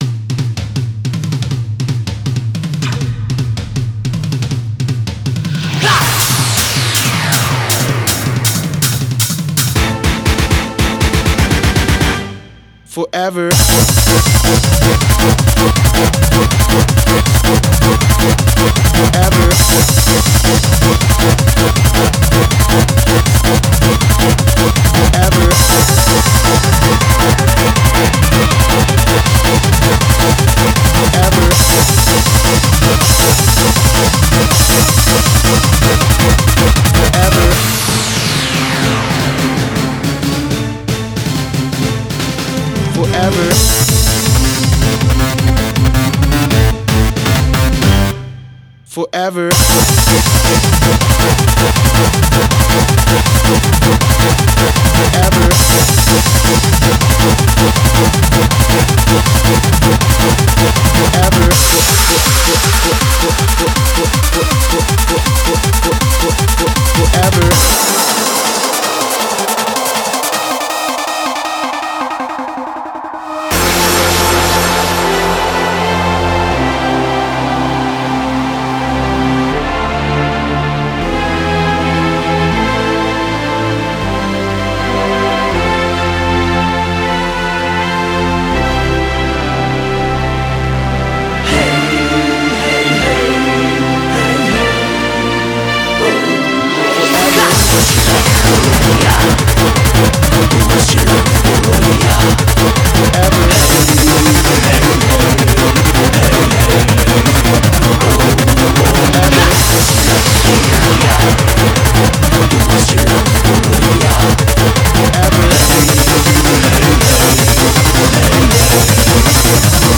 BPM40-160
MP3 QualityLine Out